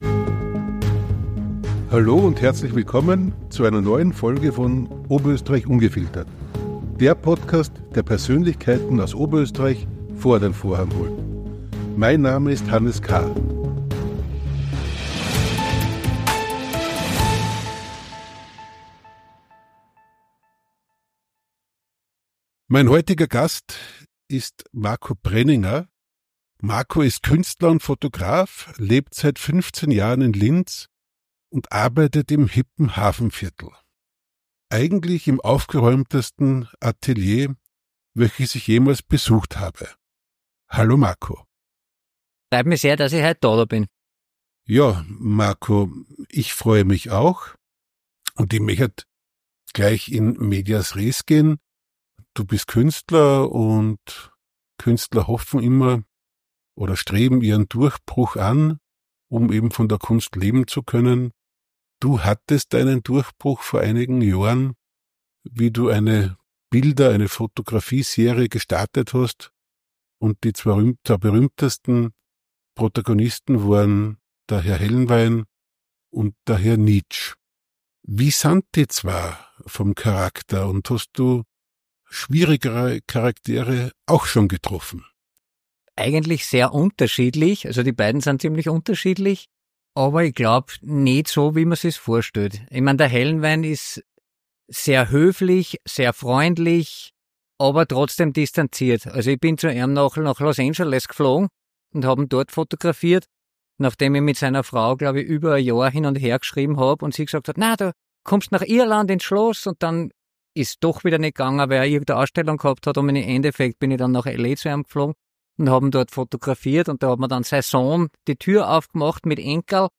In einem offenen und ehrlichen Gespräch